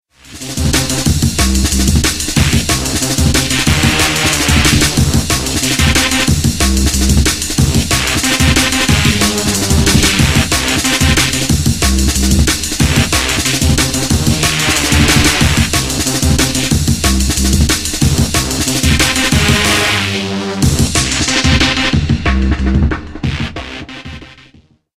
Genre: Drum and Bass